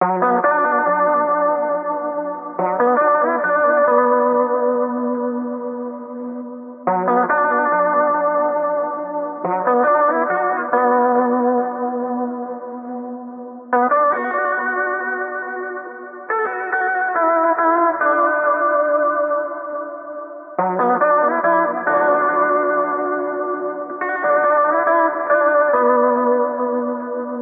木吉他 " Dm和弦变奏曲
描述：我在我的Hohner古典吉他上演奏Dm和弦的变奏曲
Tag: 尼龙 声学 霍纳 吉他 变异 拨弦 古典 字符串 DM